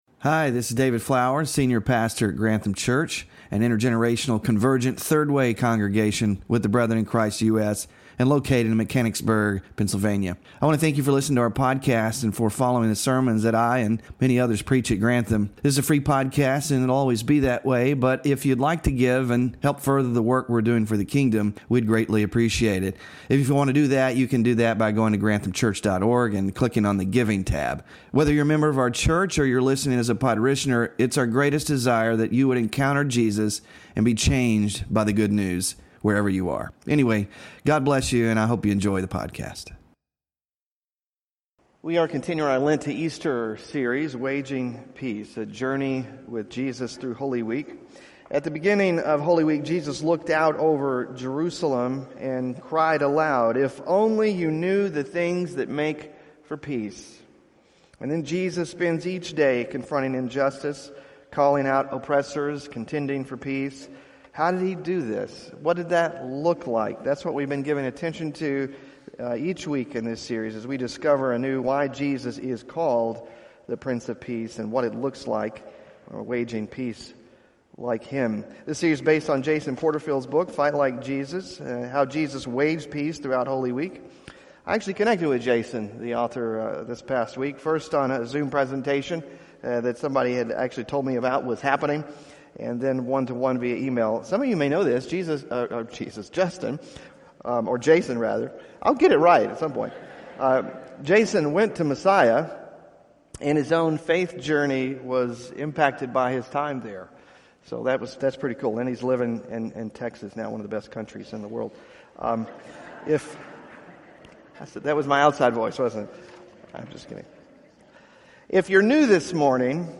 WORSHIP RESOURCES Waging Peace (5 of 7) Sermon Slides Small Group Discussion Questions